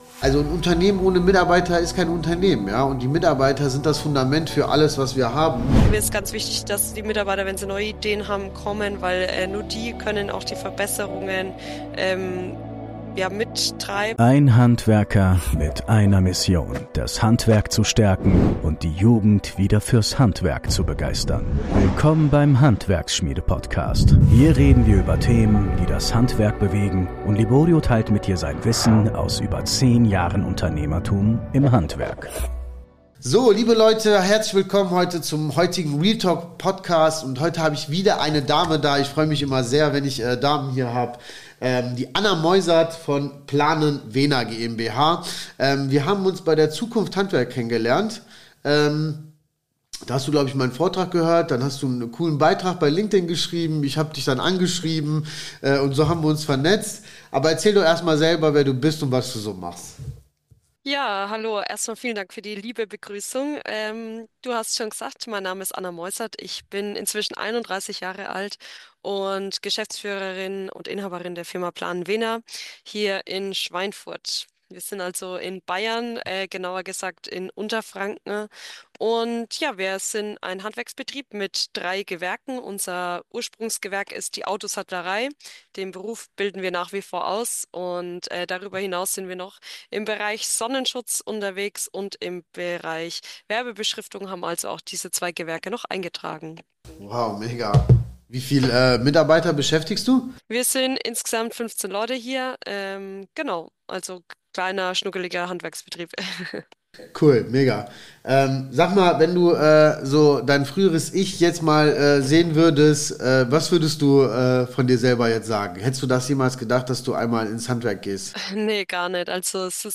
Wie du dein Team aufbaust, motivierst und langfristig hältst | Interview